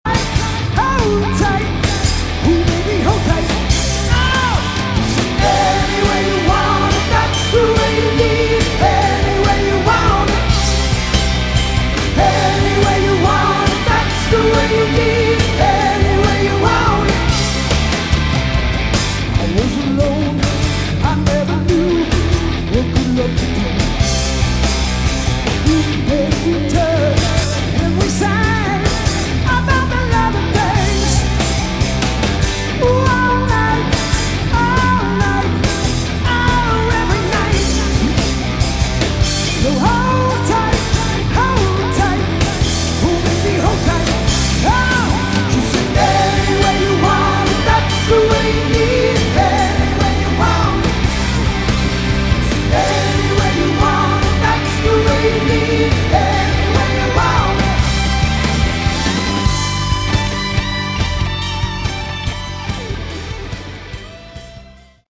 Category: Melodic Rock / AOR
guitar
keyboards, vocals
bass
drums, vocals